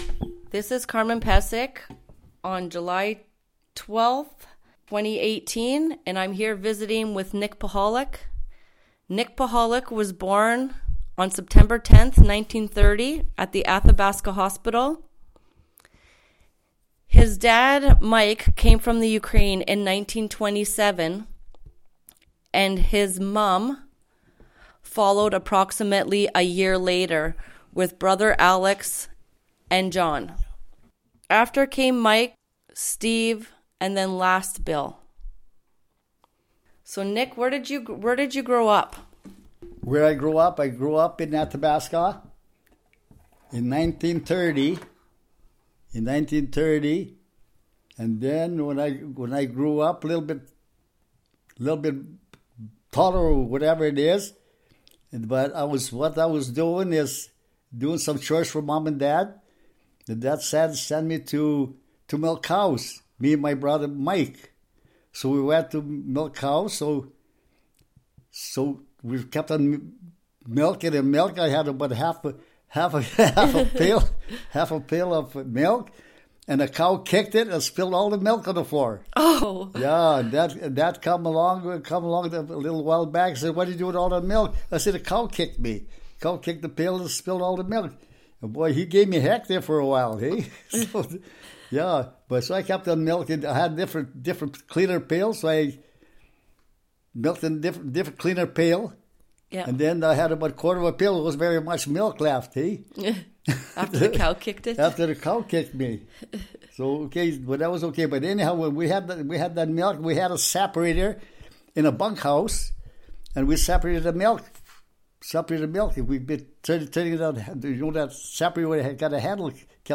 Audio interview and obituary,